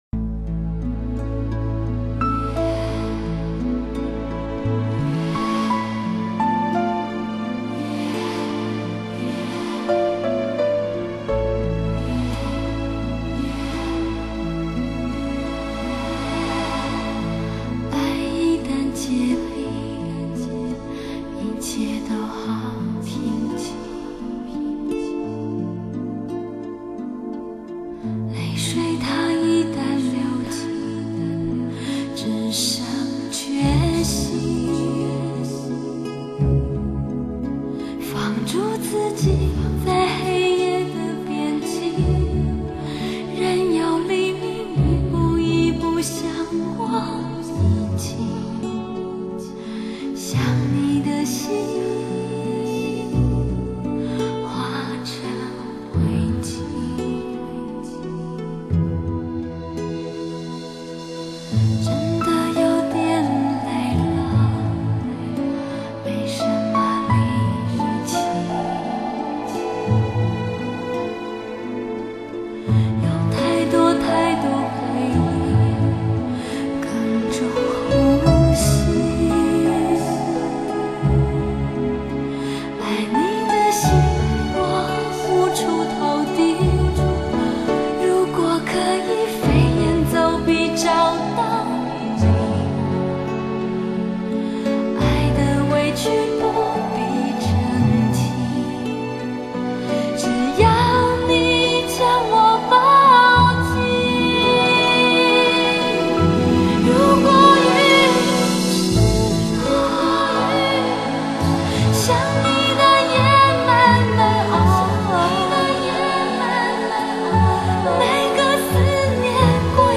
日本JVC母带制作,原始母带高清数码还原技术重制,全新的数码音响技术，使经典更醇香，歌者更光彩！